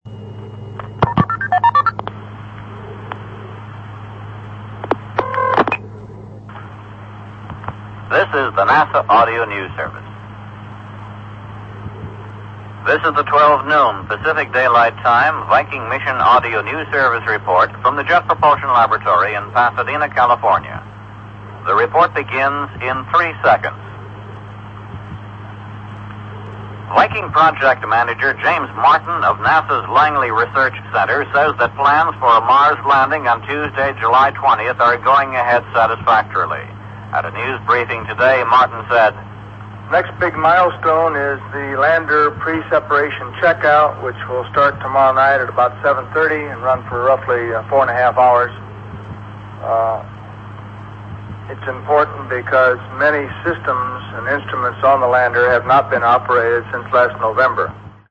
In the lead up to the Viking 1 landing, I recorded several brief news reports from the recorded JPL audio news service in Pasadena.
Here’s one 60 second item, complete with the progress tones at the start, and the charging tone as the call is connected: